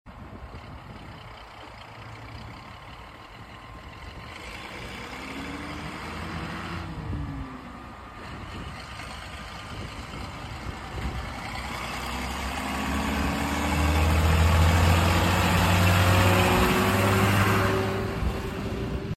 Fiatagri New Holland 100 90 pure sound effects free download
Fiatagri New Holland 100-90 pure sound🔊